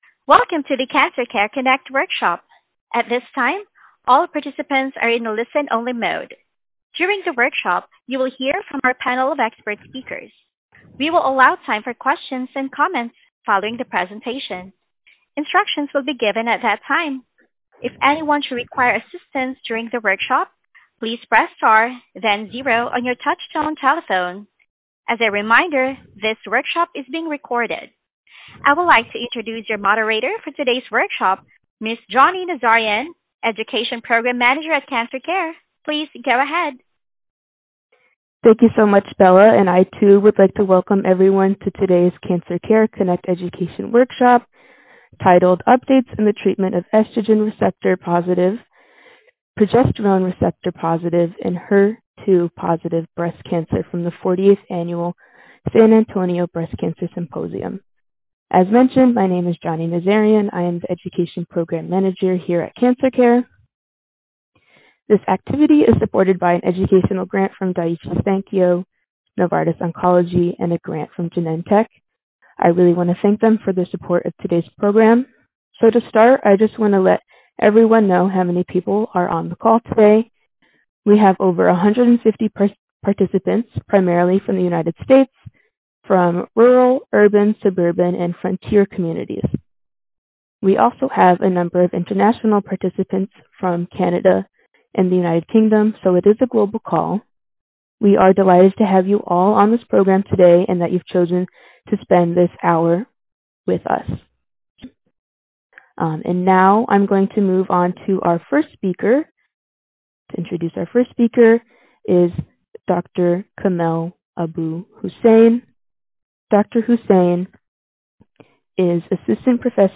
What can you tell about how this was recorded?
This workshop was originally recorded on February 25, 2026.